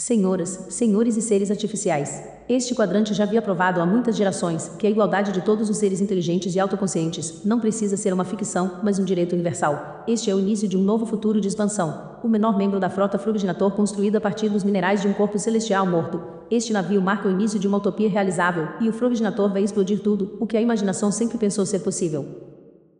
Ich habe verschiede Sprachsynthesen für die Vertonung getestet.
;) Und nicht über die Sprachgeschwindigkeit wundern, denn FrogBots sind das gewohnt.
Test - IBM Watson: